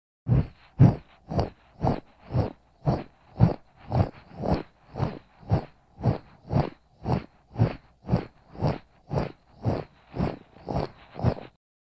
Звуки мультяшных шагов
Гуп гуп